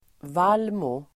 Ladda ner uttalet
vallmo substantiv, poppy Uttal: [²v'al:mo] Böjningar: vallmon, vallmor Definition: stor röd blomma tillhörande släktet Papaver poppy substantiv, vallmo Förklaring: stor röd blomma tillhörande släktet Papaver